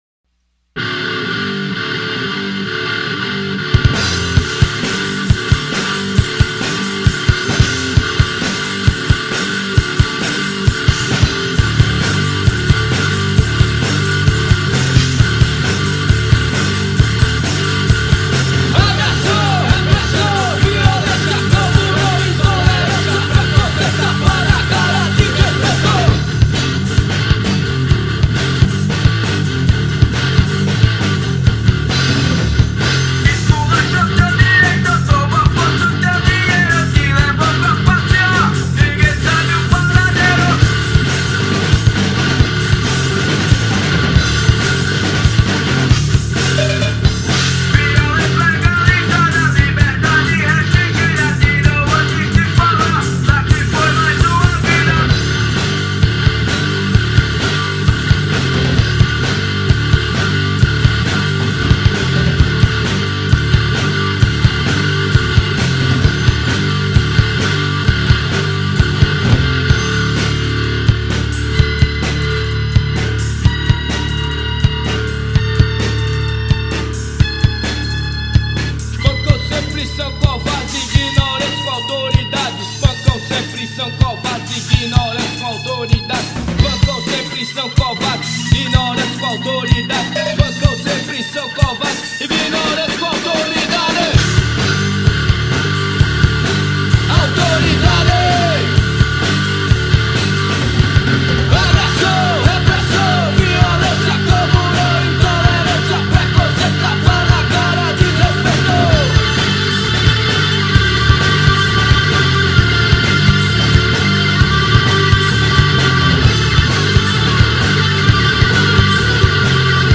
Baixo
Guitarra
Bateria
Vocal . Letras
Som direto, sem excesso, com peso e intenção.